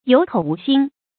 注音：ㄧㄡˇ ㄎㄡˇ ㄨˊ ㄒㄧㄣ
有口無心的讀法